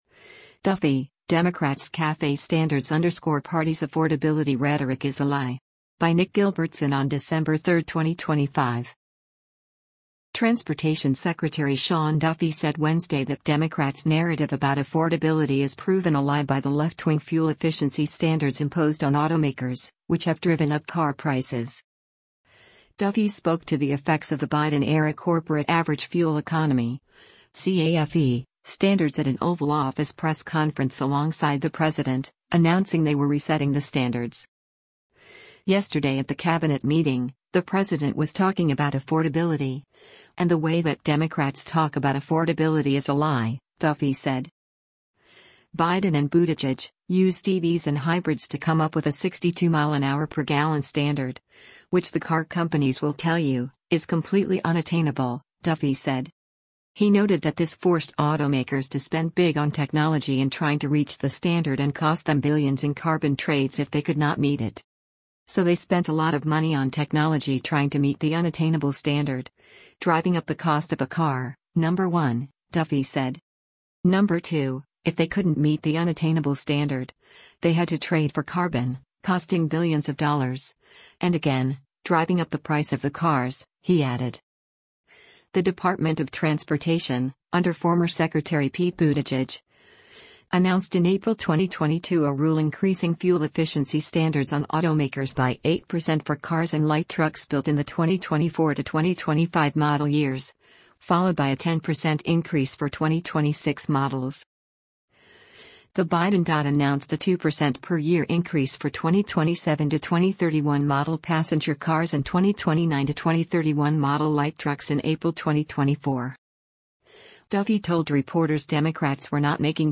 Duffy spoke to the effects of the Biden-era Corporate Average Fuel Economy (CAFE) standards at an Oval Office press conference alongside the president, announcing they were resetting the standards.